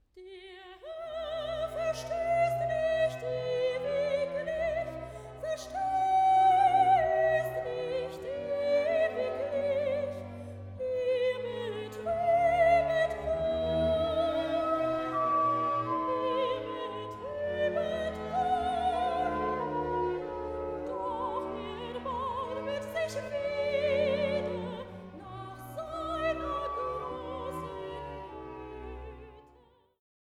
Arioso (Eine israelitische Jungfrau)